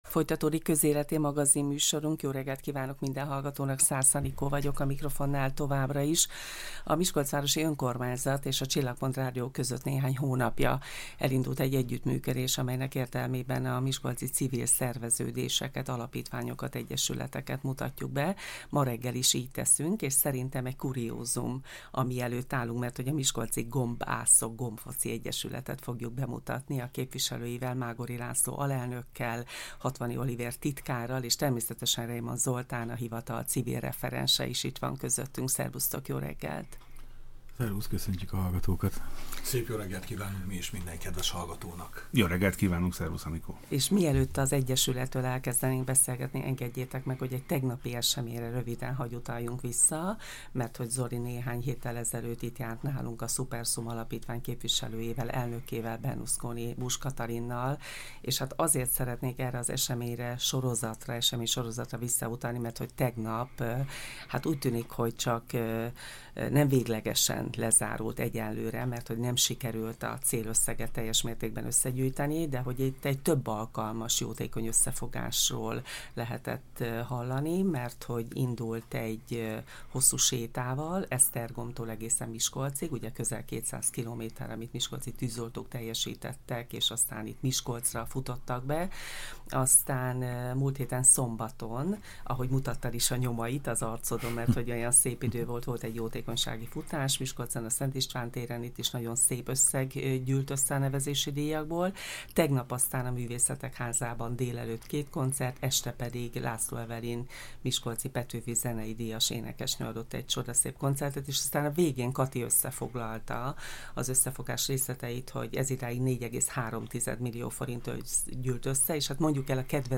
lokálpatrióták beszélgettek